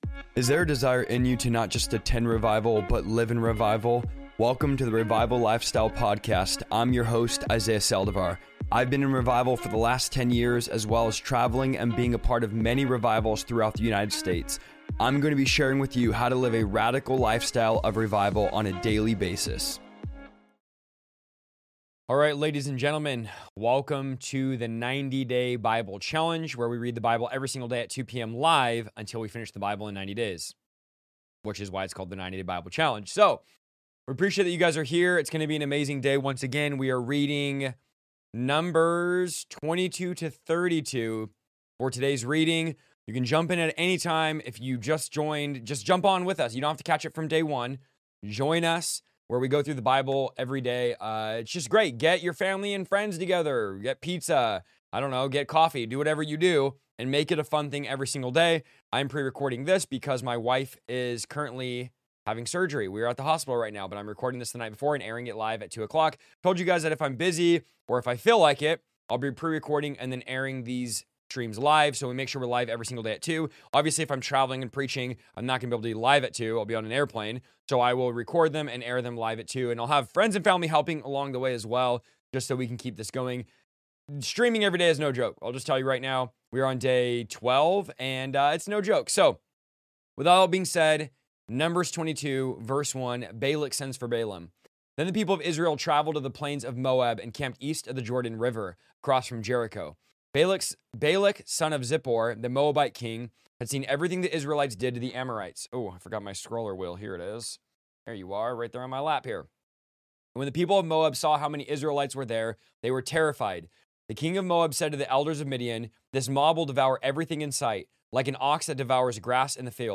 I am going to be LIVE everyday at 2 PM for 90 days straight reading through the entire Bible!